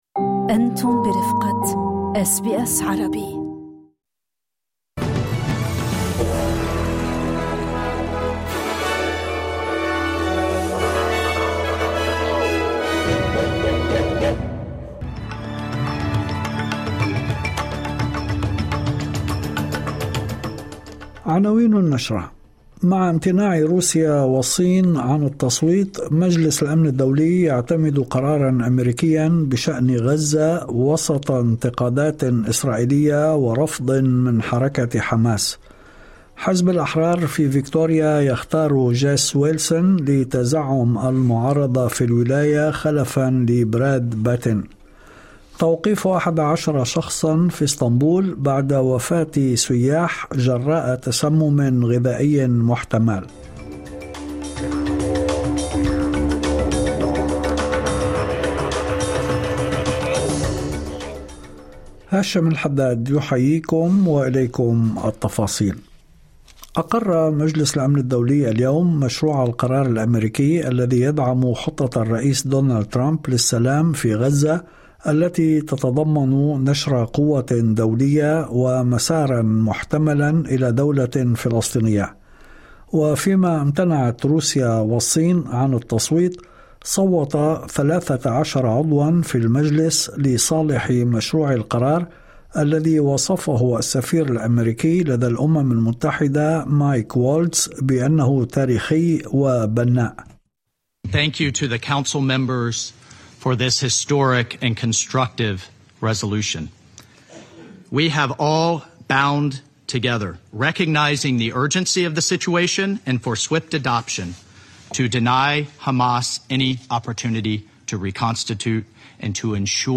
نشرة أخبار المساء 18/11/2025